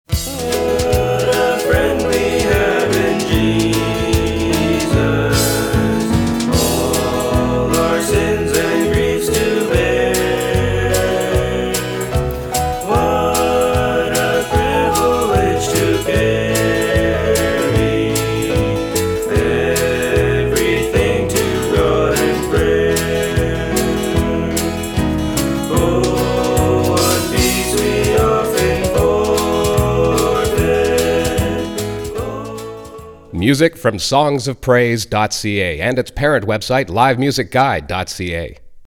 Gospel Music
country gospel songs